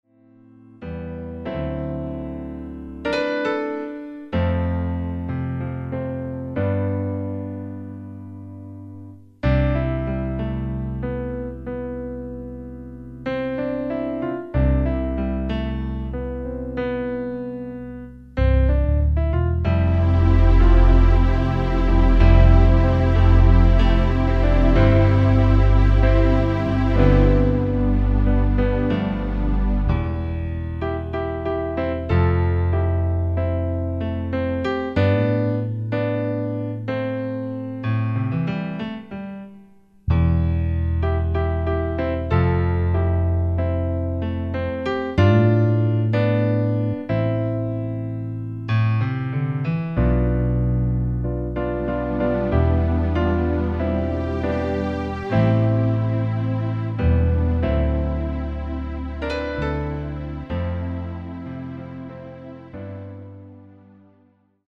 Key of C